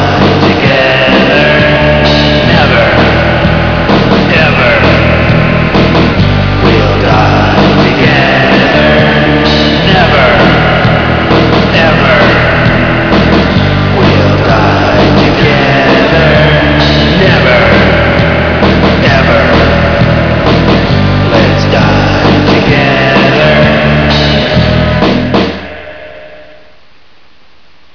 experimental, strange,and exotic sound